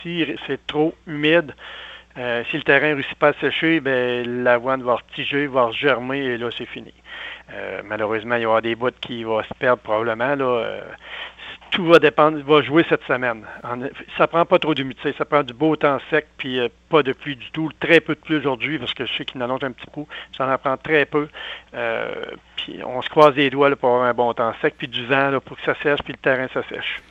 Le préfet de la MRC de Bécancour qui est aussi agriculteur, Mario Lyonnais, est revenu sur ce qu’il a malheureusement pu constater dans la région.